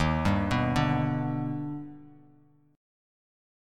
Eb+M7 Chord
Listen to Eb+M7 strummed